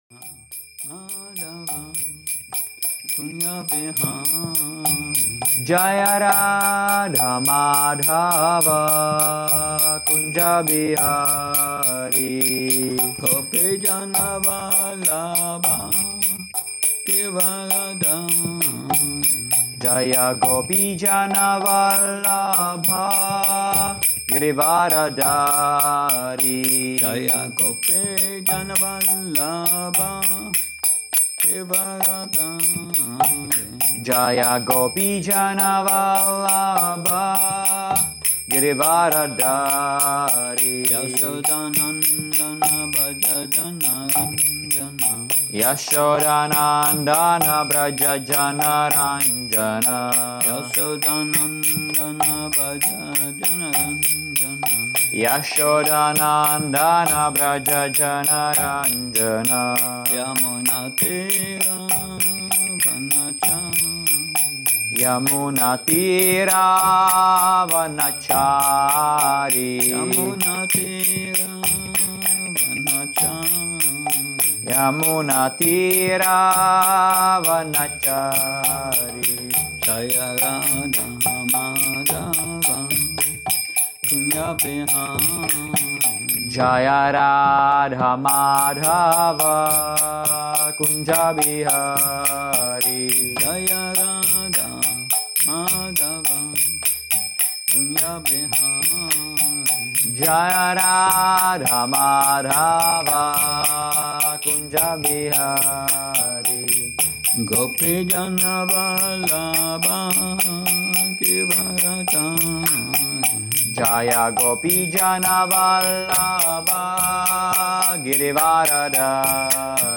Šrí Šrí Nitái Navadvípačandra mandir
Přednáška